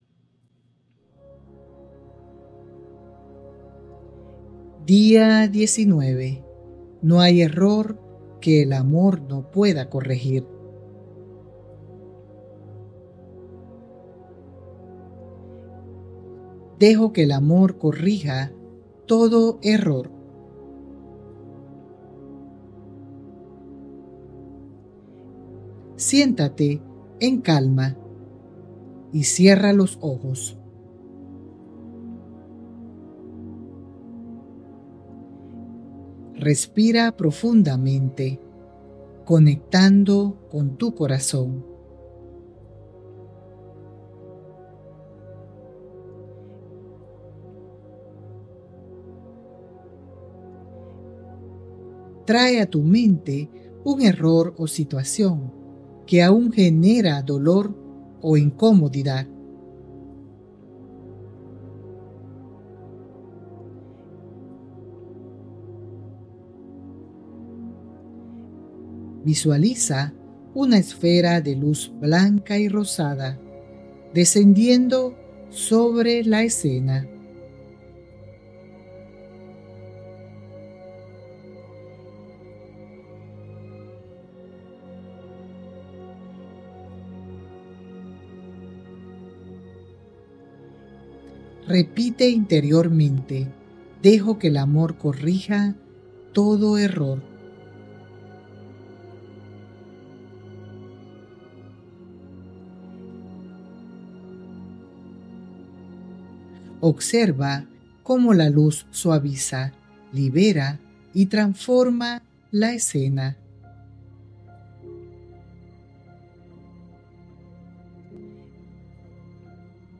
🎧 Meditación Guiada: «Dejo que el amor corrija todo error»